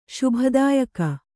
♪ śubha dāyaka